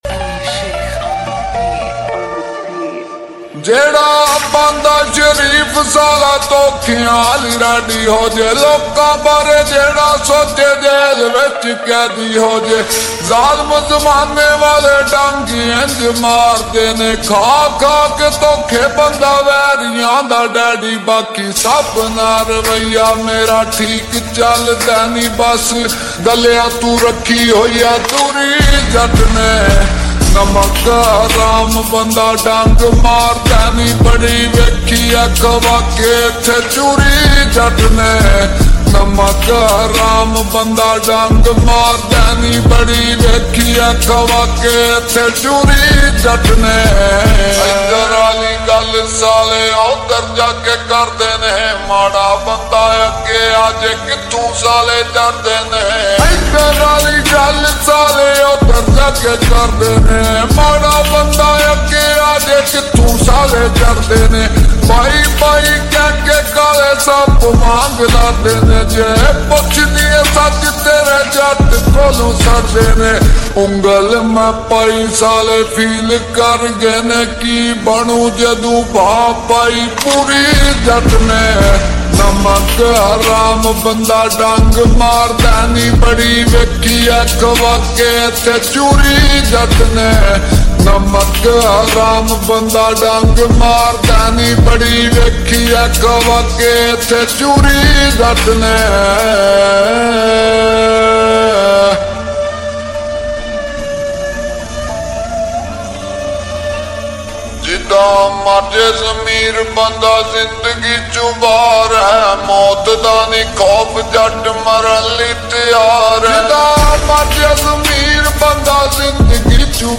full song Slowed and Reverb